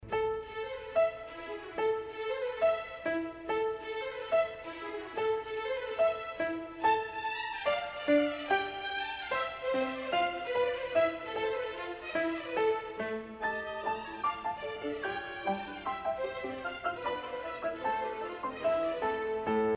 Tema: L’istesso tempo